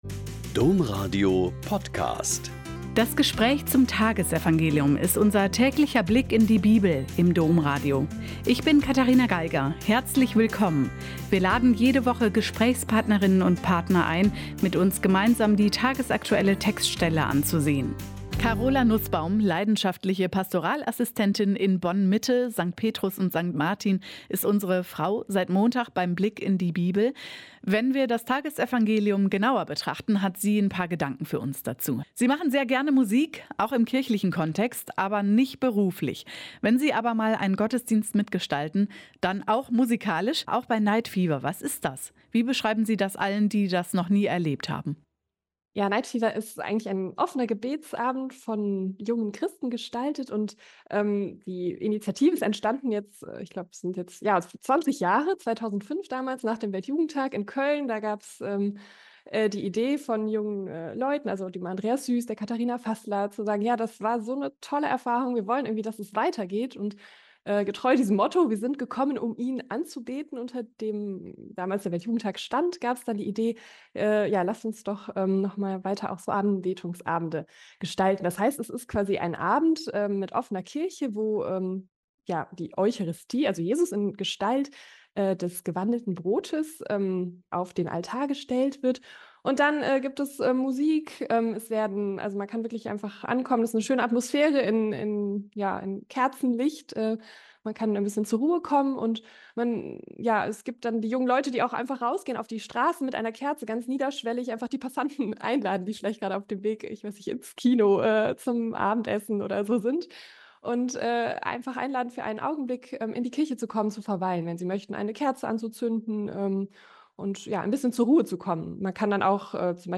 Mk 3,13-19 - Gespräch